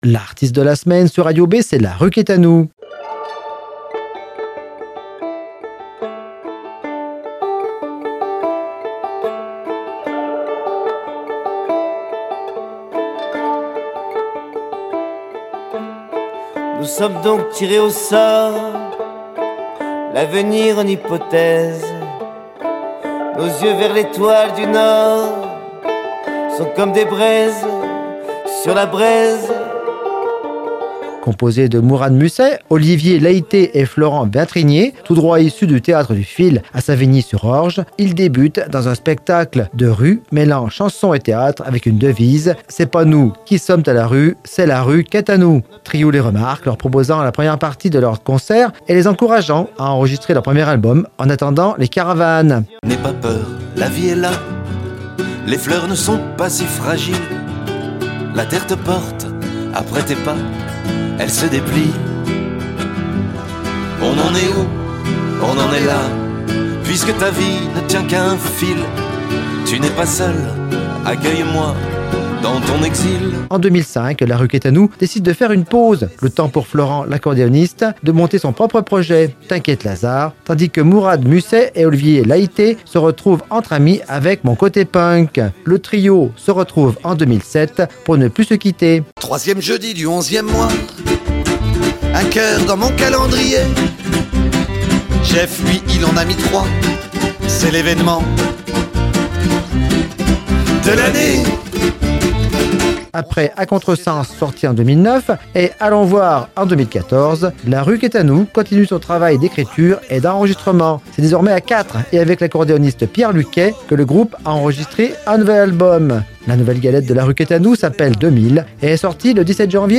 Vingt ans de cavale, de chansons festives, réalistes et engagées, de guitares, d'airs d'accordéon, d'harmonica et de percussions endiablées.
Chansonniers et poètes, funambules acoustiques extravagants, ils sont suivis par un public toujours aussi fidèle et nombreux.